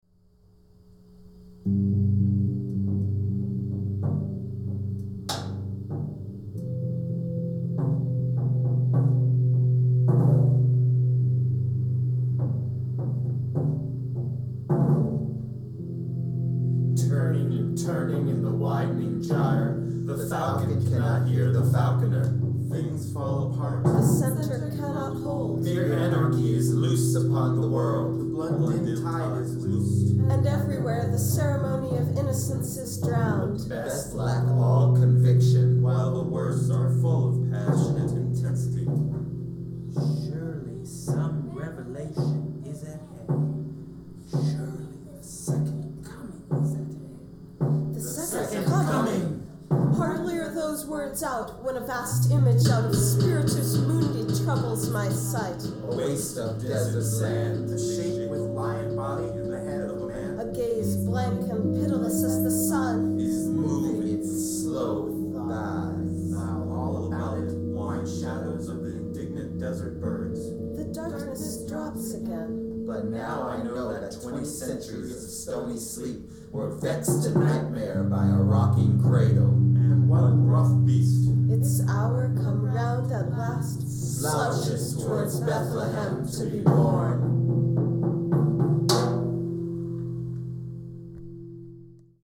A caravan moving across the desert; people pacing in between the wagons; horses pawing the dust.